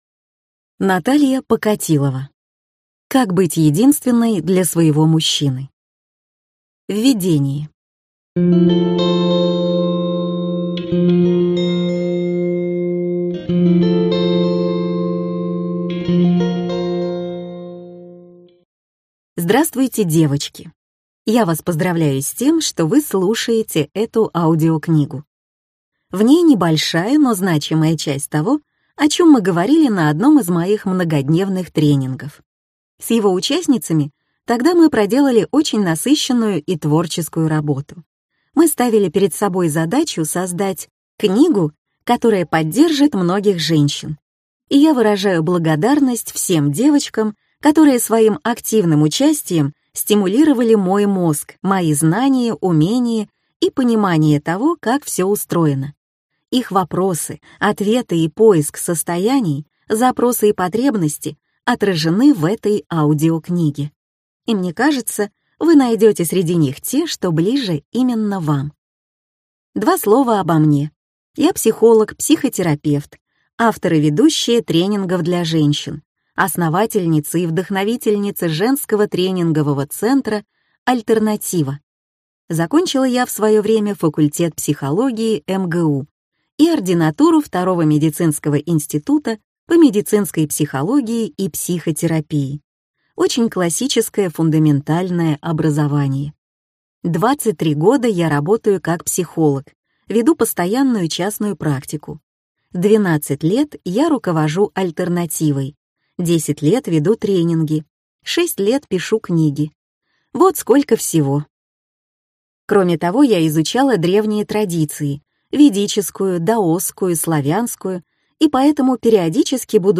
Аудиокнига Как быть единственной для своего мужчины | Библиотека аудиокниг